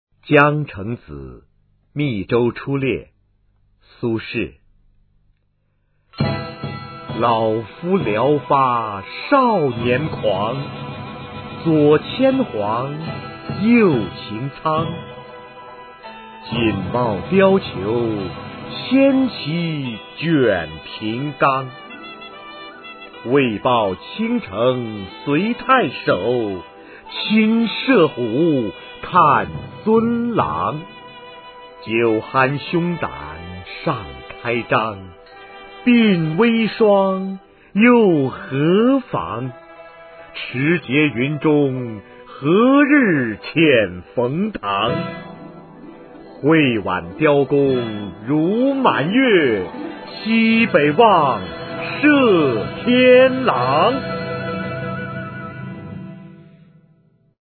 《江城子·密州出猎》原文和译文（含赏析、朗读）　/ 苏轼